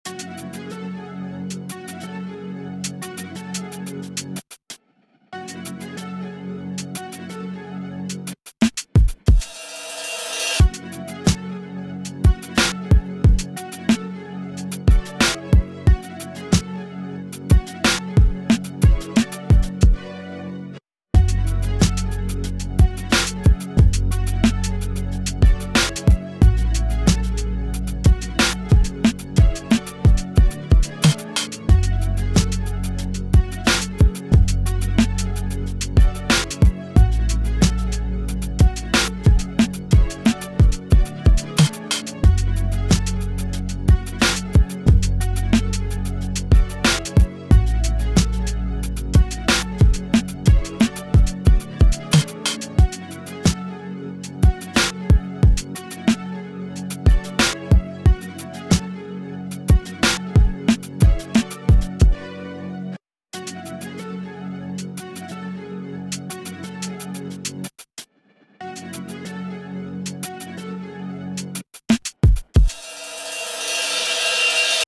Dramatisches Hiphop Sample
Auch rhythmisch mag ich das, Oddmeter ist cool.
Anhang anzeigen 143546 ne Stunde etwa zum choppen loop bauen und komplett lieblos in einer minute arrangiert XD hab nur 8 pads verwendet. ungefähr die ersten 10 Sekunden. ich finds gooil Also nicht den Beat den ich da gebaut hab, sondern was damit so alles geht!